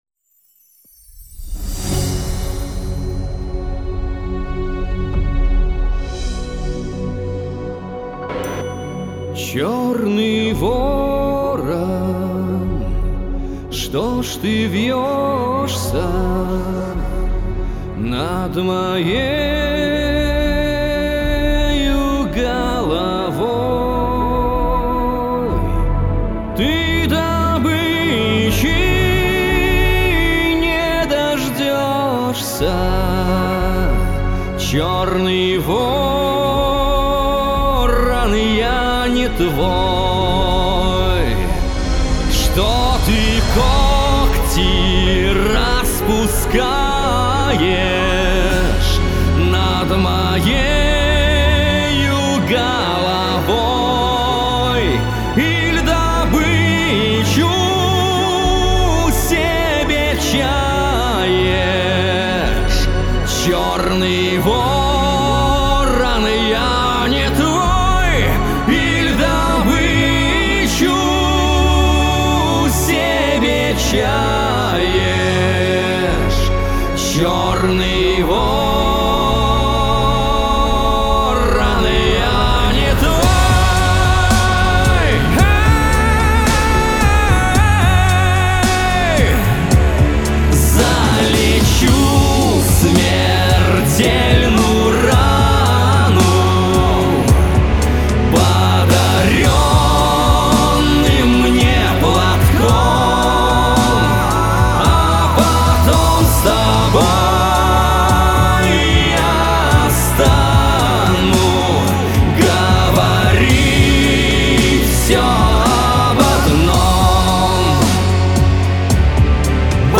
Мощность и красота редкостная... для любого сайта)